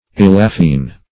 Search Result for " elaphine" : The Collaborative International Dictionary of English v.0.48: Elaphine \El"a*phine\, a. [Gr.